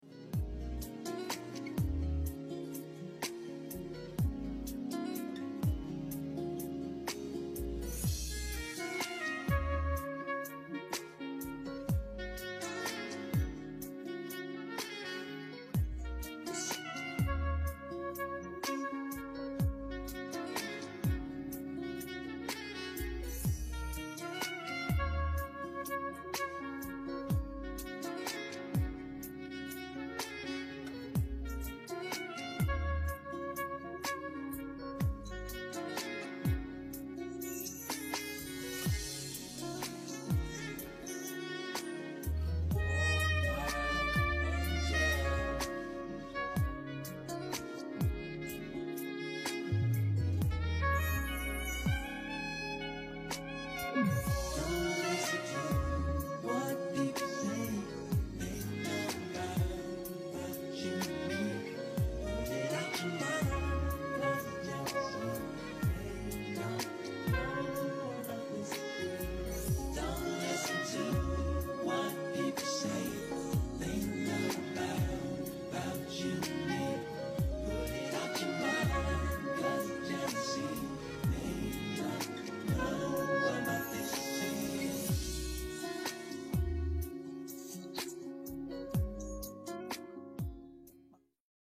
sax